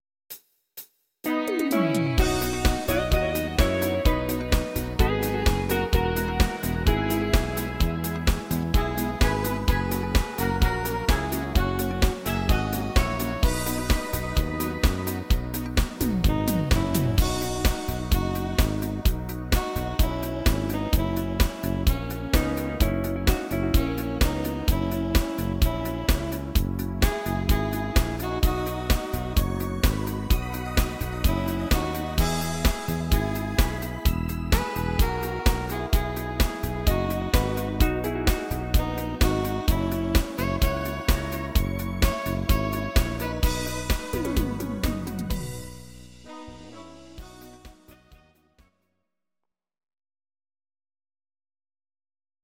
These are MP3 versions of our MIDI file catalogue.
Please note: no vocals and no karaoke included.
Your-Mix: Volkstï¿½mlich (1262)